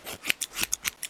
haircut5.wav